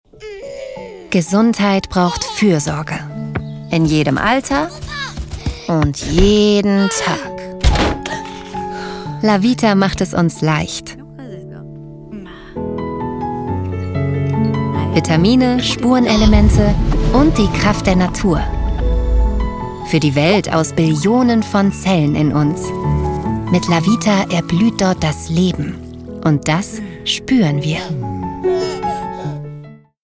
Erzählung
Julia Biedermann (Berlin) spricht Pyramid Game Julia Biedermann - Schauspielerin und Sprecherin.